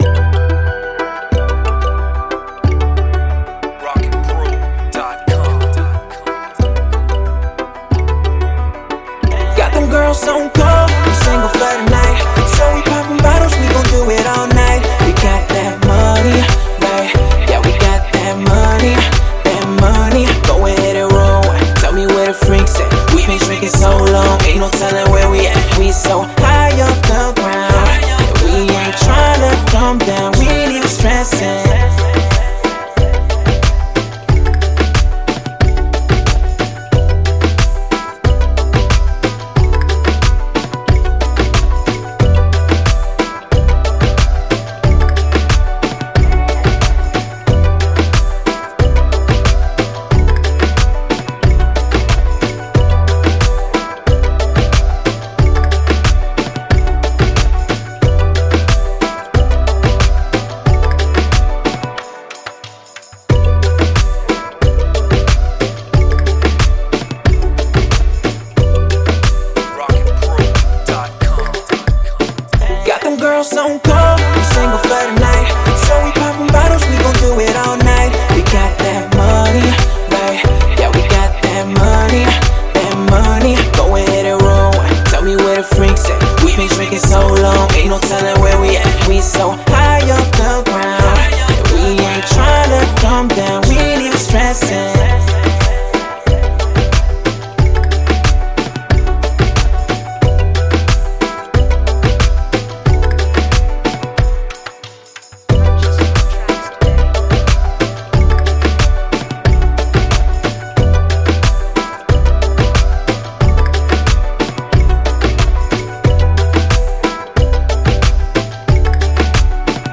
91 BPM.
club style hook
with hook
Club
Pop